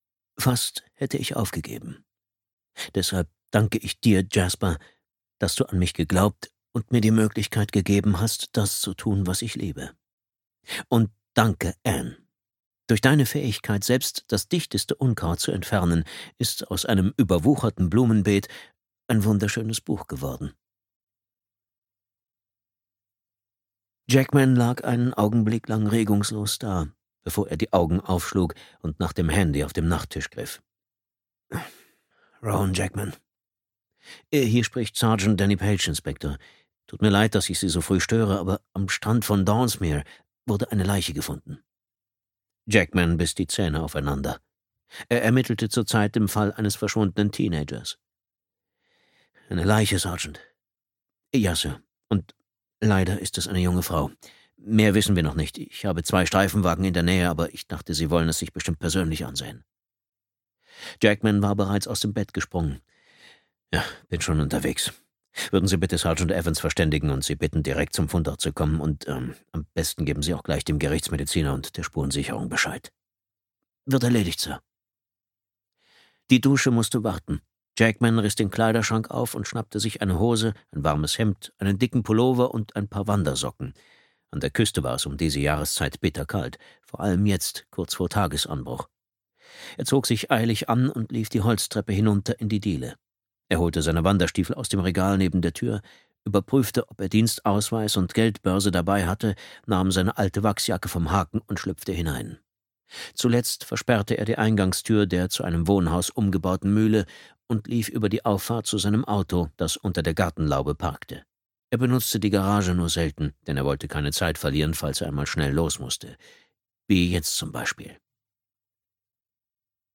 Die verschwundenen Töchter (DE) audiokniha
Ukázka z knihy